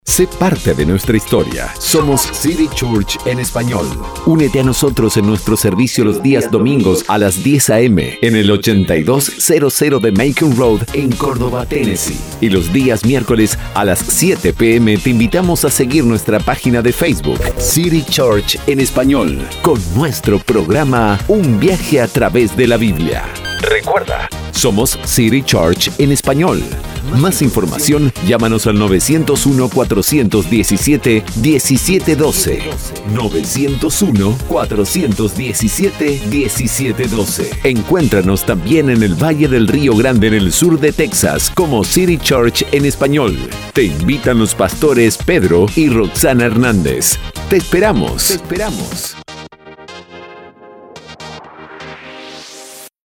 1. spot para radio latina en Tennesse Grabada en Neutro para población latina.
2. TONO GRAVE – INSTITUCIONAL